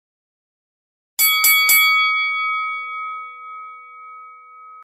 دانلود صدای زنگ شروع مسابقه بوکس 1 از ساعد نیوز با لینک مستقیم و کیفیت بالا
جلوه های صوتی